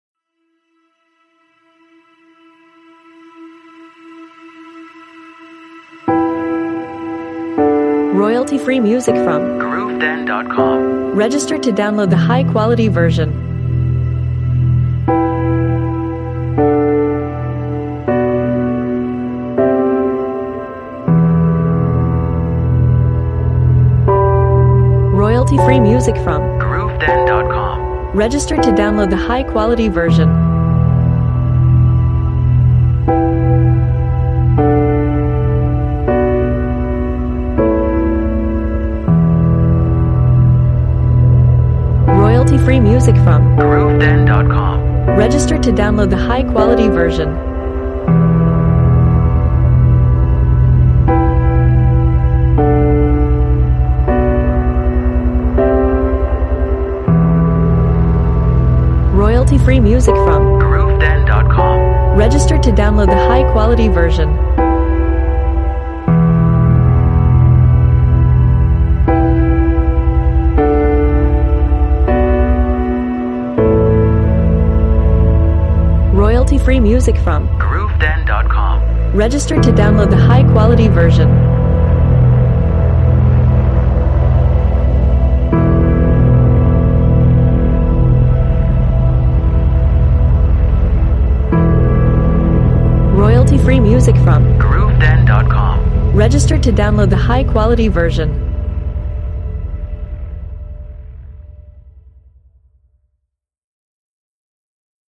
Very tense and dark composition.